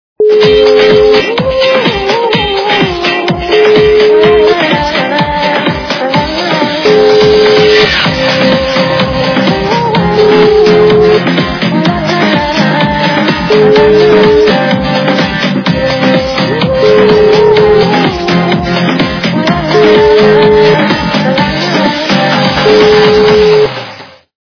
западная эстрада